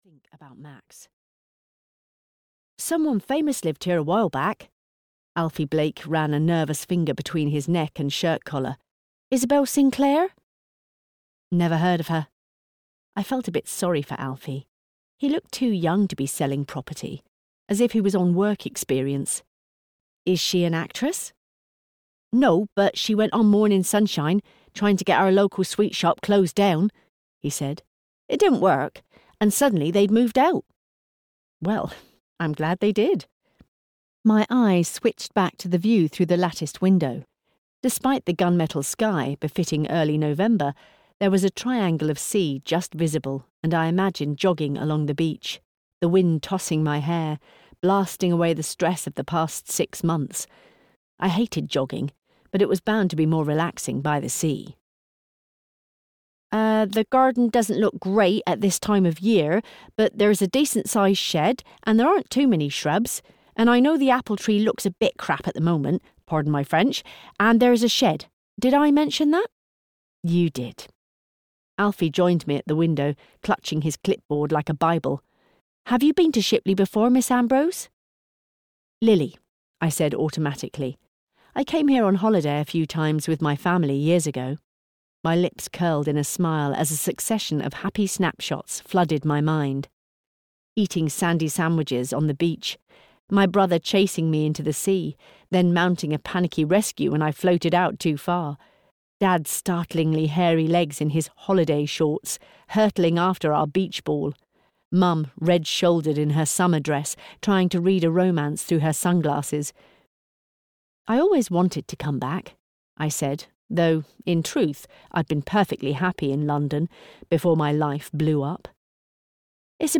The Beachside Christmas (EN) audiokniha
Ukázka z knihy